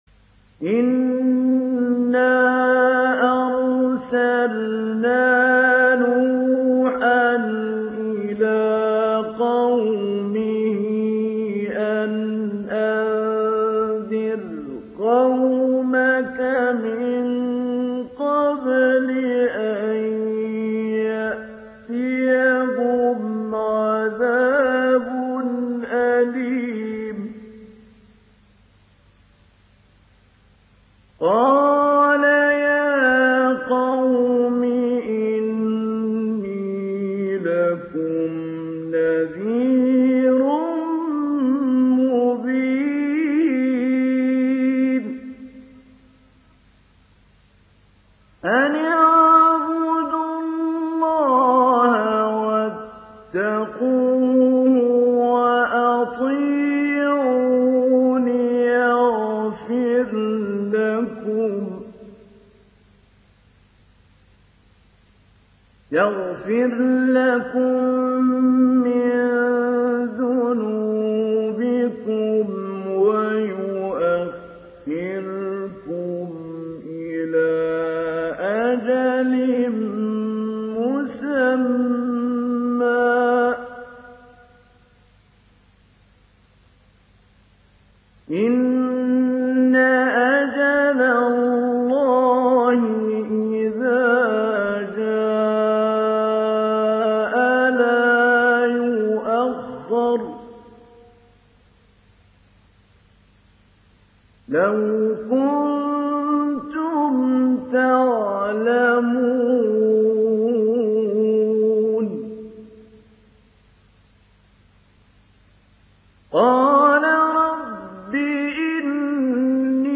ডাউনলোড সূরা নূহ Mahmoud Ali Albanna Mujawwad